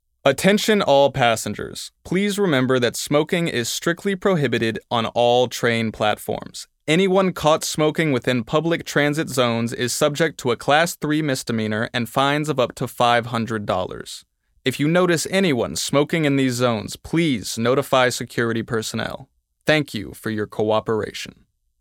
Male
Yng Adult (18-29)
Studio Quality Sample
No Smoking Announcement
Words that describe my voice are Versatile, Calm, Natural.